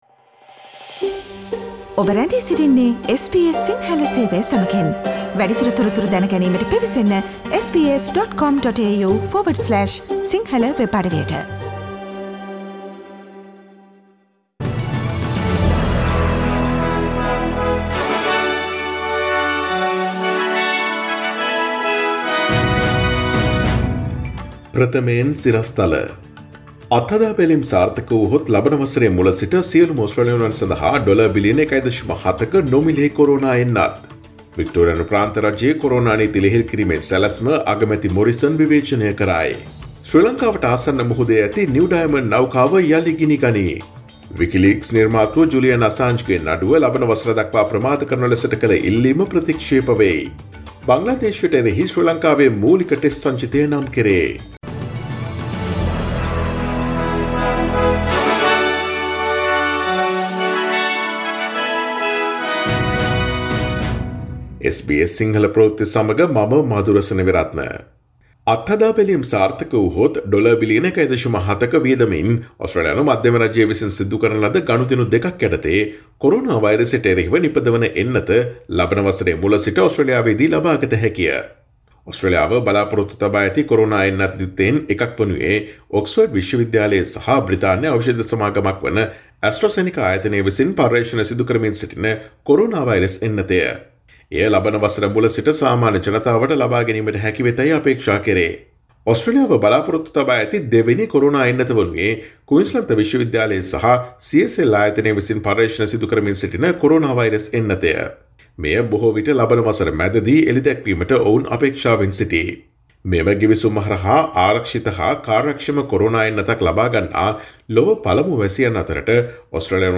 Daily News bulletin of SBS Sinhala Service: Tuesday 08 September 2020